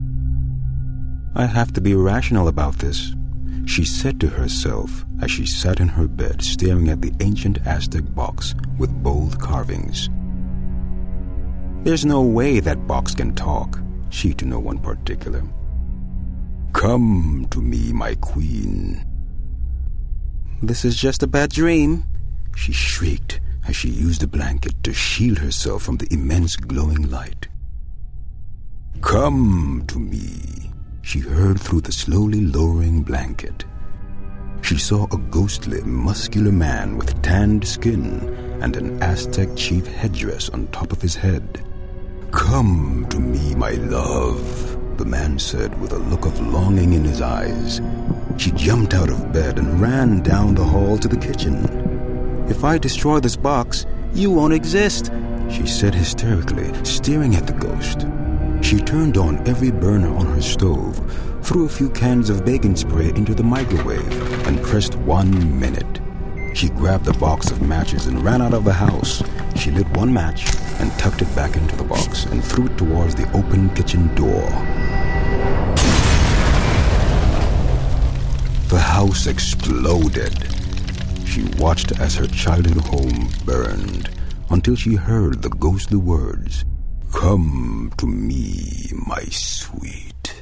My voice can be described as a booming bass/baritone that projects authority and trust, inspires and educates, but also entertains and engages—with the ability to perform ages ranging from young adult to senior and dialects in both American and Jamaican accents.